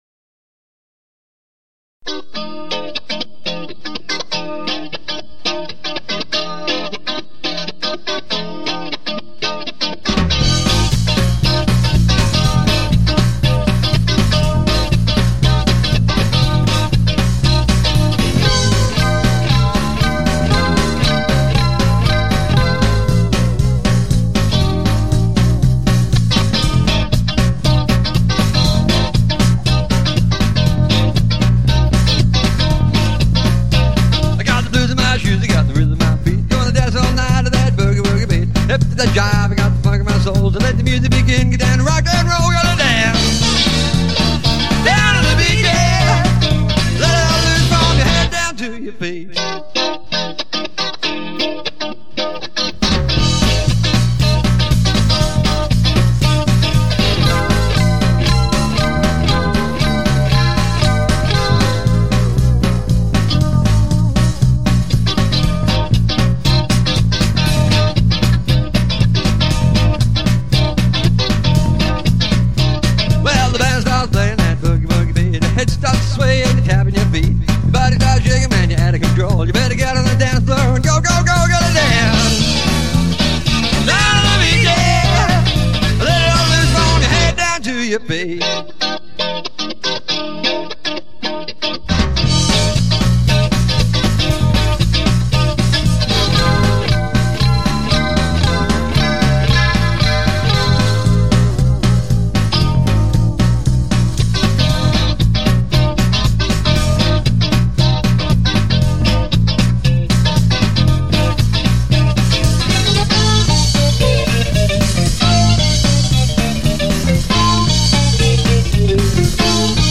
guitar/vocals
drums/lead vocals
bass/vocals.